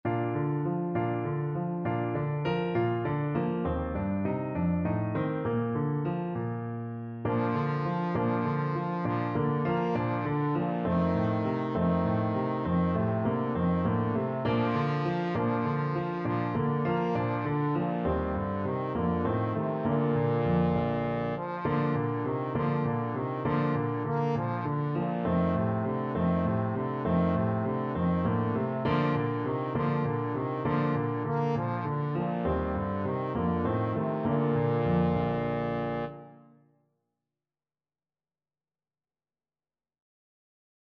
Trombone version
6/8 (View more 6/8 Music)
Moderato
Bb3-Bb4